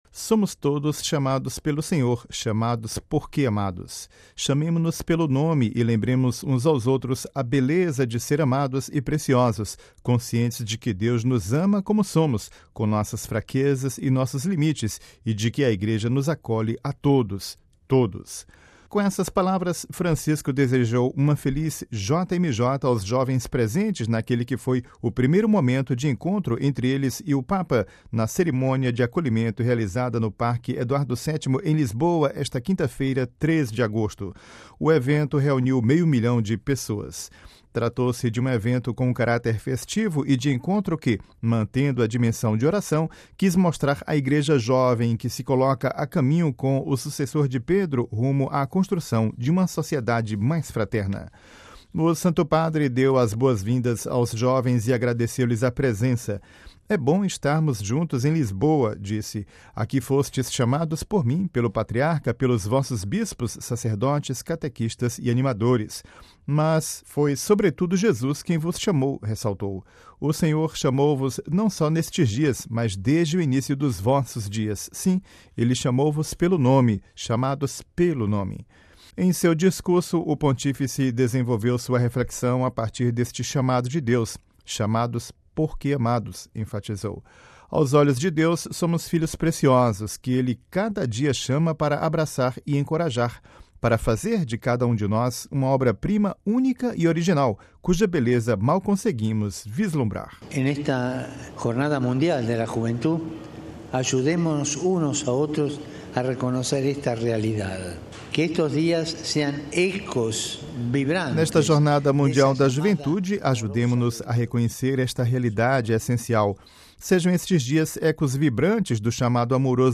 Cerimônia de acolhida da JMJ, no Parque Eduardo VII, em Lisboa (Vatican News)
Ouça a reportagem com a voz do Papa e compartilhe